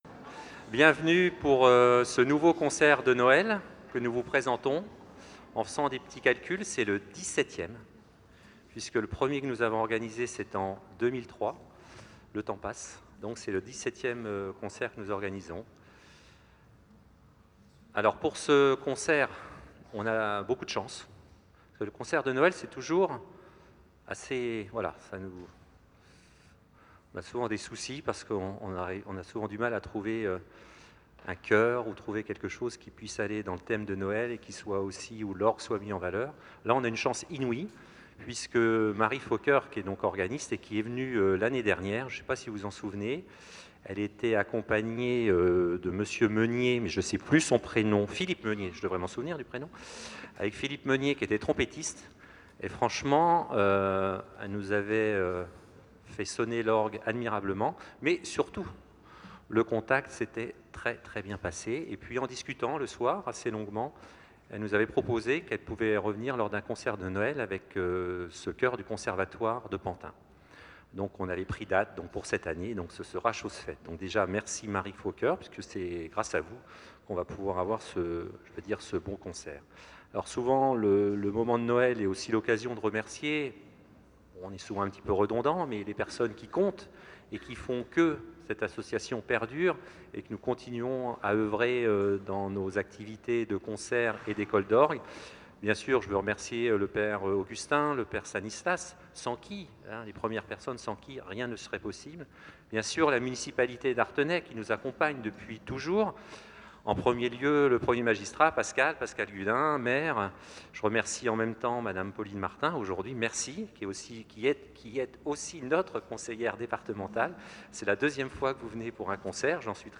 CONCERT DE NOËL 2019
Concert de Noël 2019 Artenay Les amis de l'Orgue dimanche 08 décembre 2019